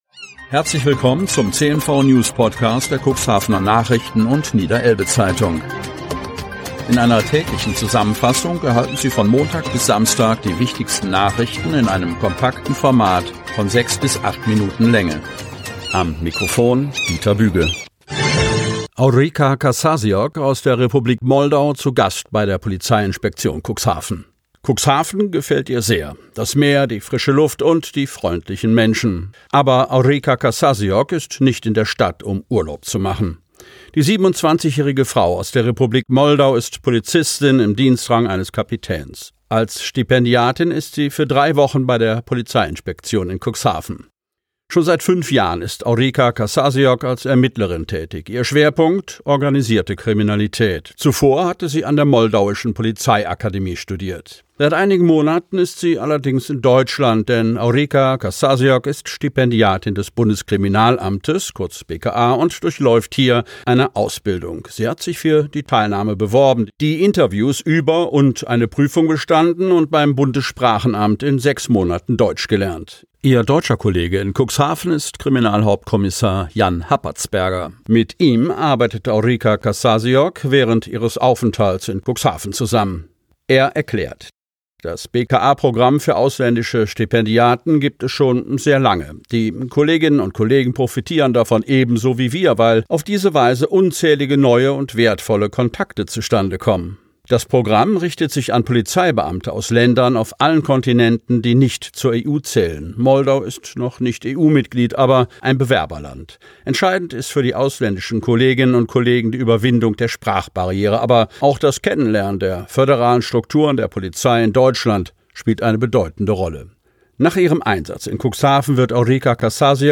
Ausgewählte News der Cuxhavener Nachrichten und Niederelbe-Zeitung am Vorabend zum Hören!